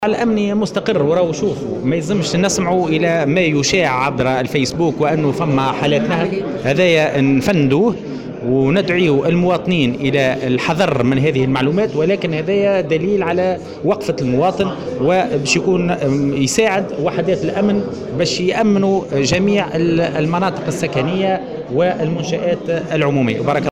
على هامش انعقاد لجنة مجابهة الكوارث